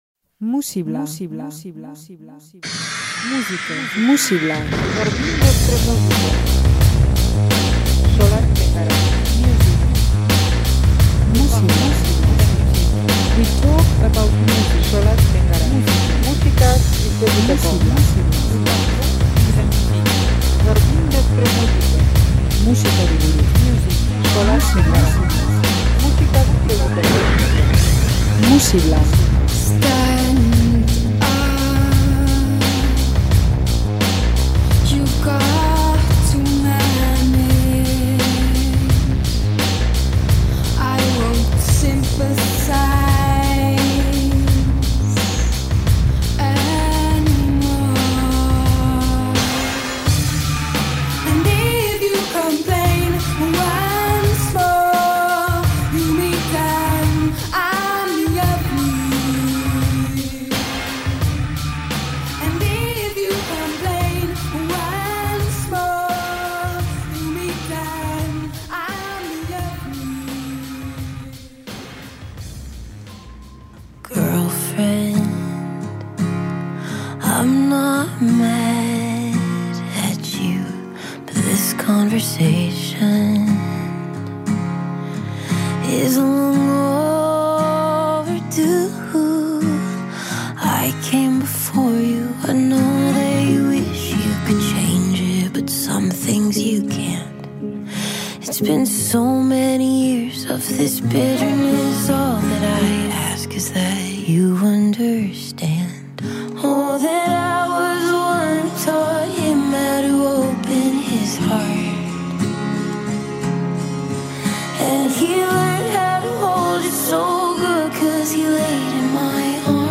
rock musikaz blai dator